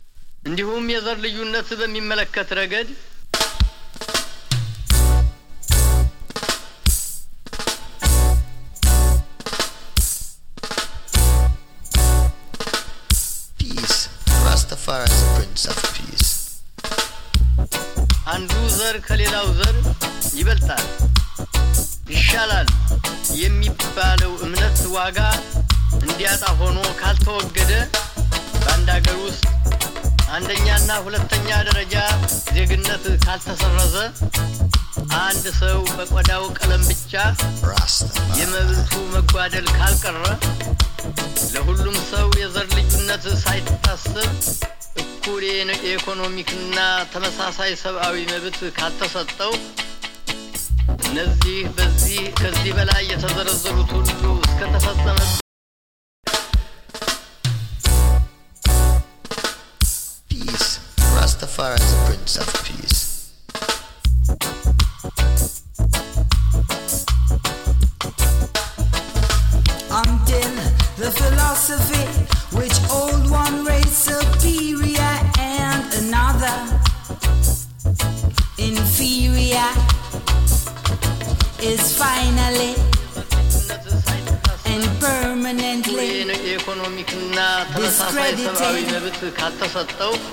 チリ、パチ、ジリノイズわずかに有り。
ROOTS ROCK